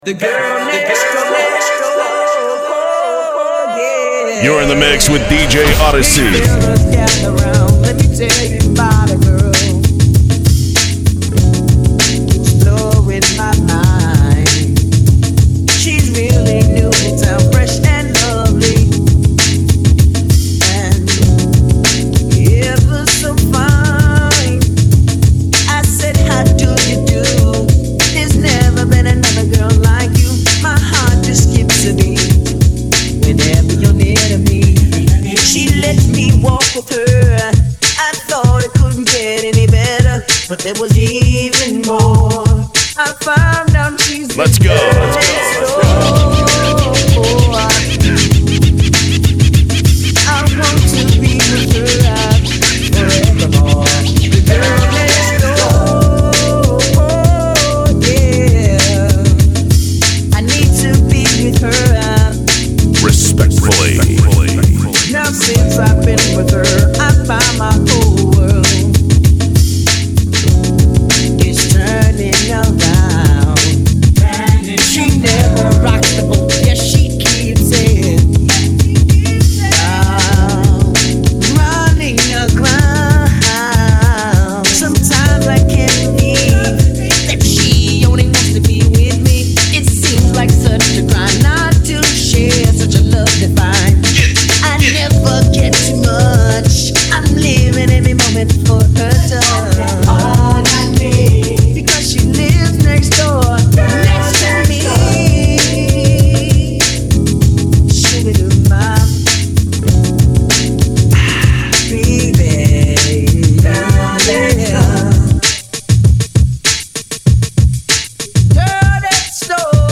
Blend Mixtape
Old School Mixtapes
Slow Jam Mixtapes